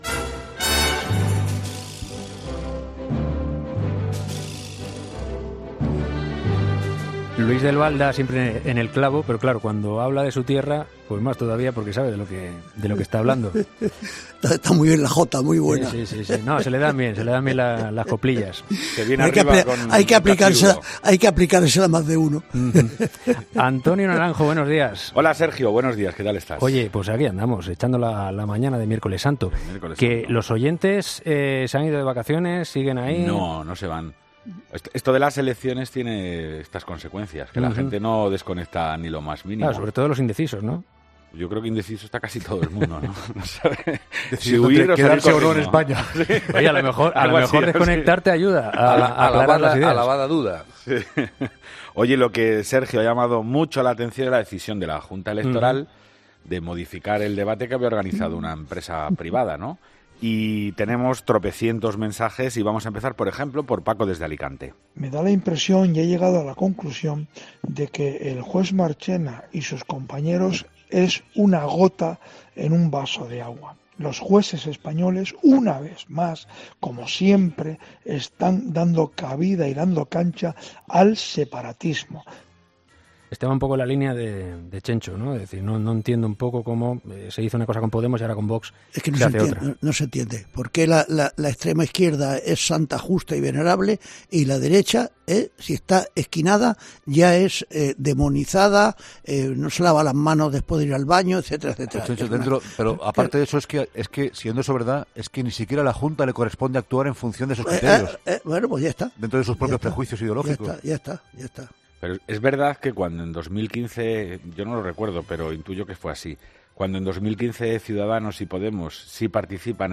La Tertulia de los Oyentes es el espacio que Herrera da a los oyentes para que opinen sobre temas de actualidad.